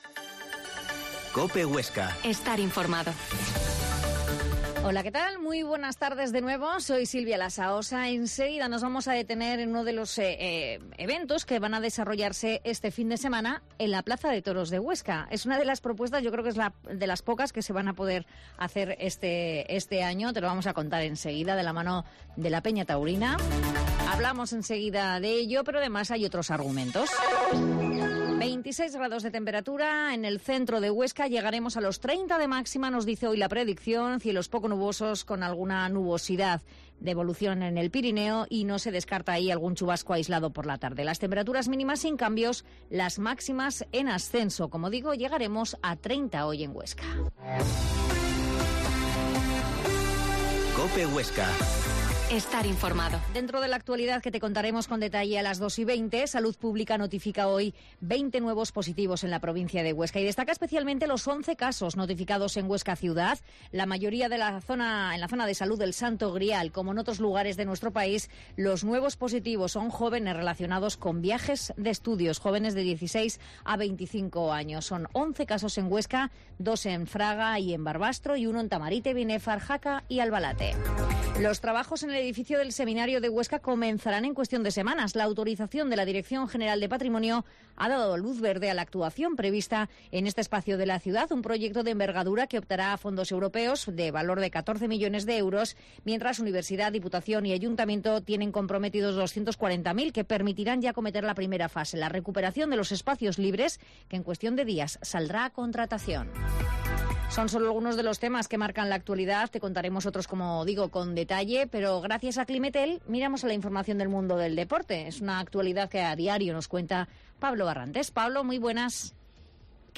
Mediodia en COPE Huesca 13.20h Entrevista al Pte.